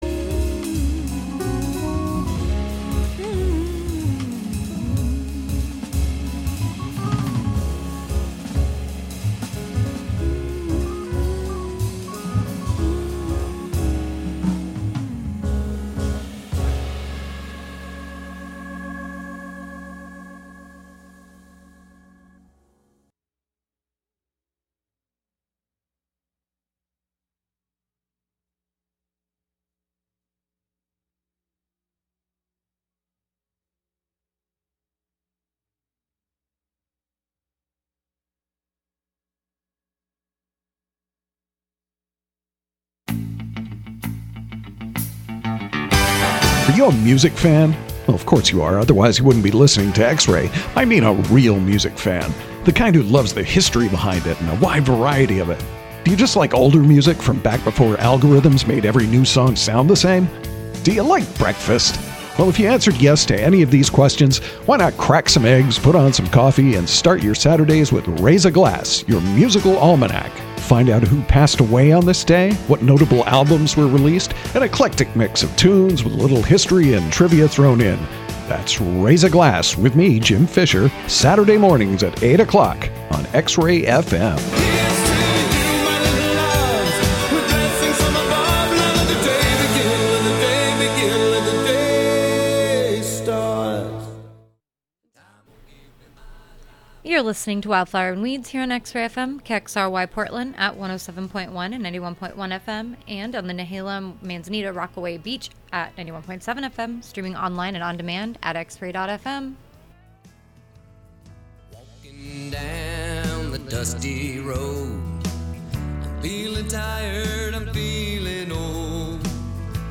A mix of independent & vintage alt, outlaw, cosmic, gothic, red dirt & honky tonk country, roots, blue grass, folk, americana, rock and roll & even desert psych and stadium hits (when acceptable).
And we get in the weeds with artist interviews & field recordings, new releases & story telling—on every Thursday 2-3pm.